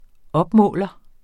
Udtale [ -ˌmɔˀlʌ ]